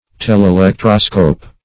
Search Result for " telelectroscope" : The Collaborative International Dictionary of English v.0.48: Telelectroscope \Tel`e*lec"tro*scope\, n. [Gr. th^le far + electro- + -scope.] Any apparatus for making distant objects visible by the aid of electric transmission.